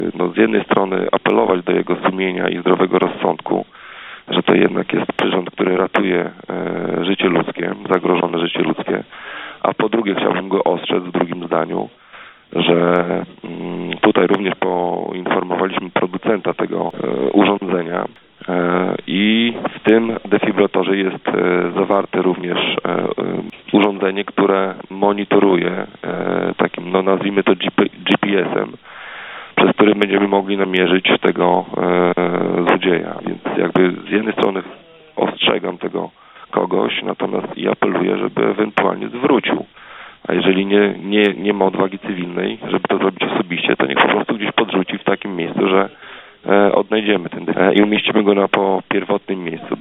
– Ukradziony został ten z budynku Regionalnego Ośrodka Kultury i Centrum Informacji Turystycznej – mówi Karol Sobczak, burmistrz Olecka.